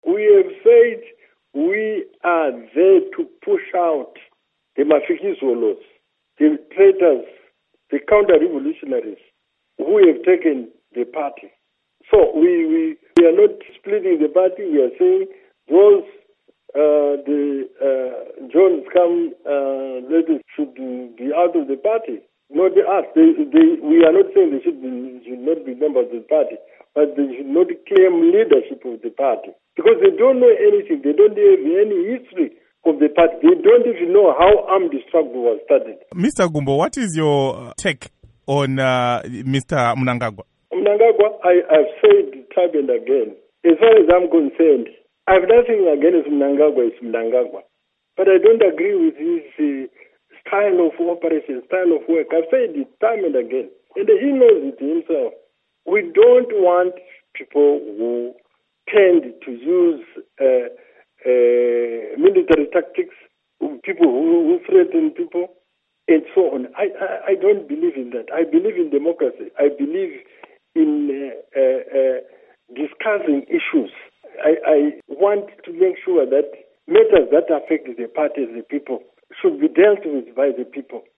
Interview With Rugare Gumbo on Zanu PF Congress, Conflicts